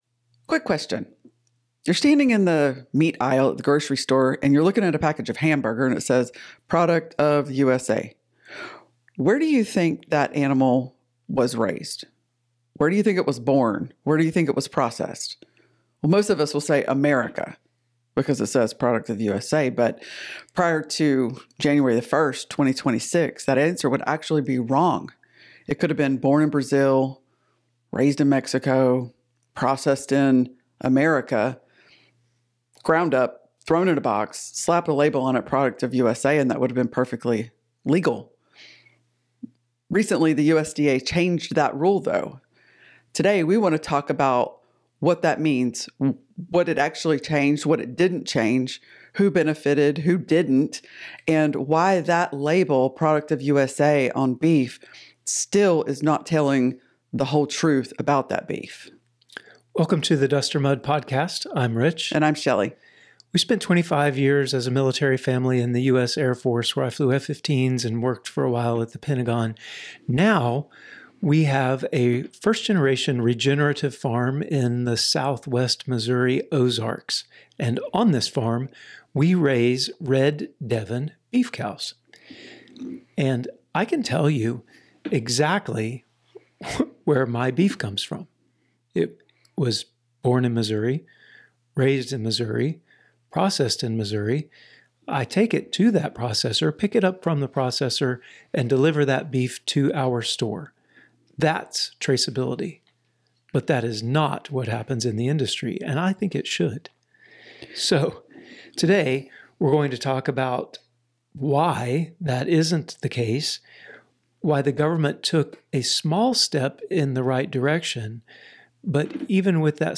This is a straight conversation about labels, lobbying, ranchers, imports, and your right to know where your food comes from.